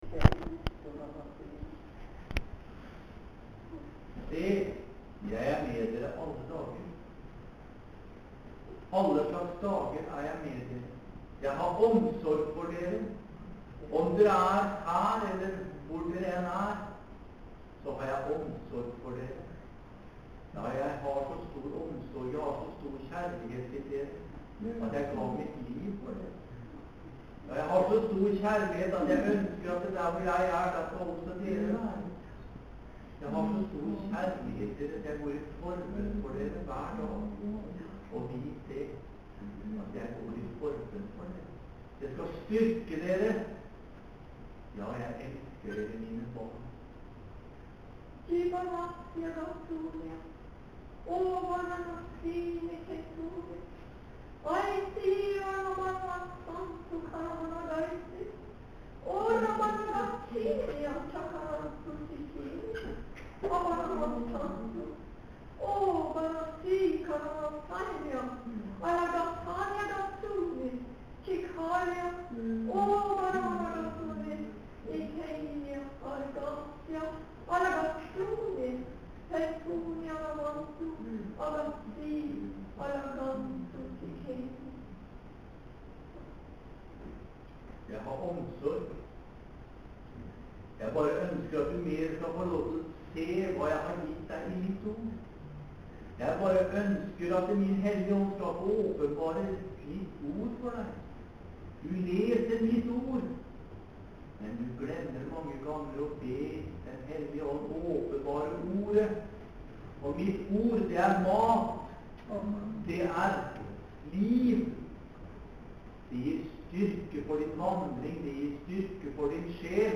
Møte på Betel i Volda, torsdag. 24.5.07.
Tyding av tungetale: